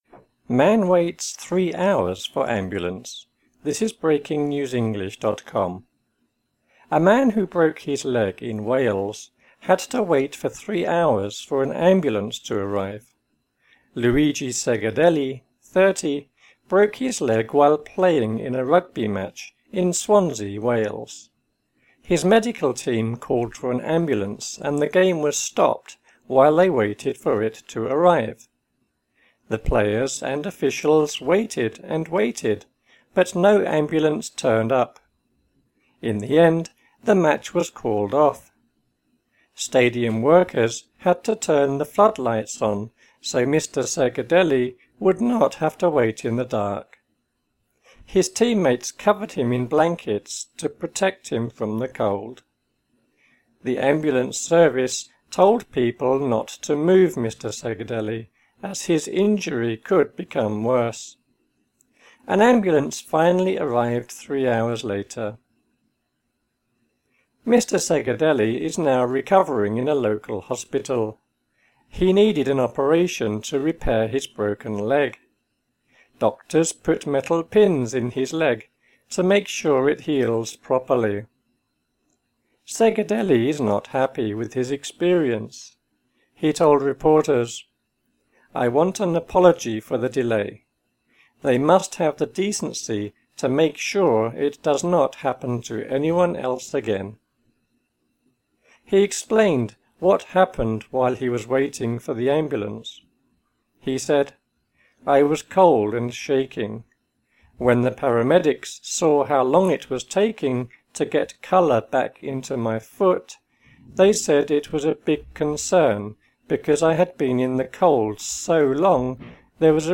slower speed)